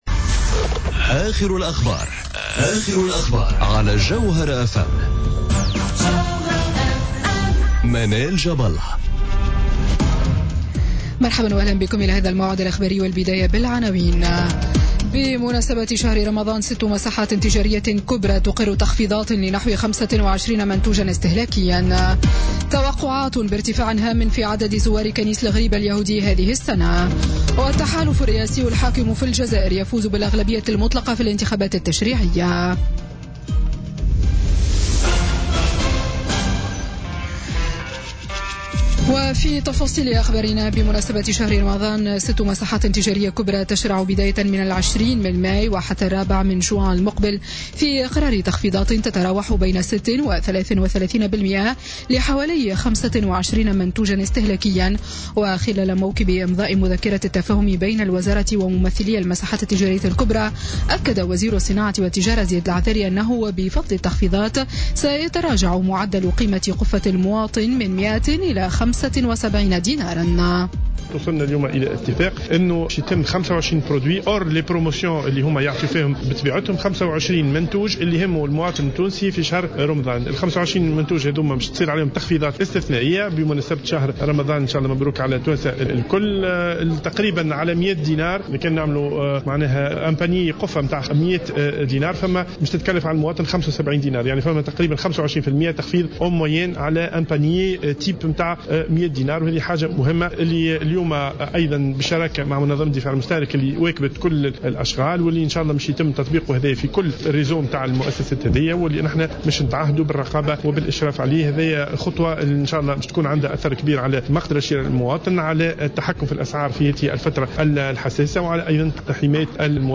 نشرة أخبار السابعة مساء ليوم الجمعة 5 ماي 2017